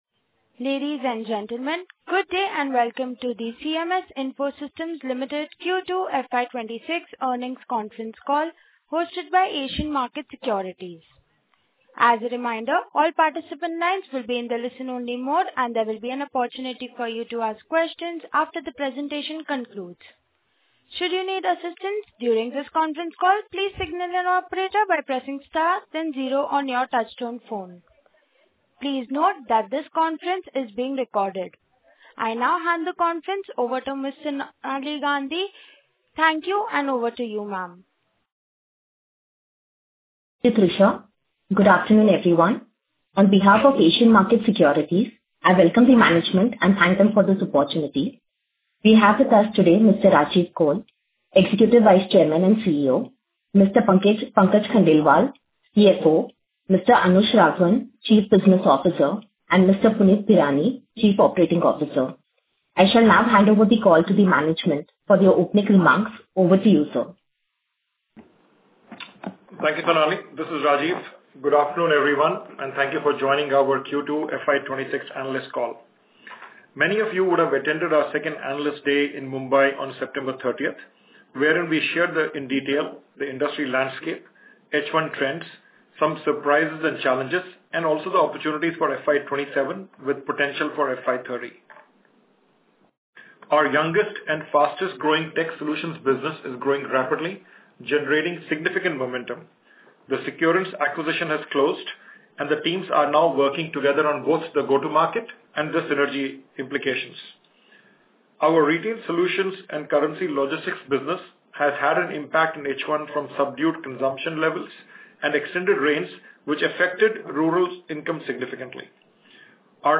q2h1-fy26-concall-audio.mp3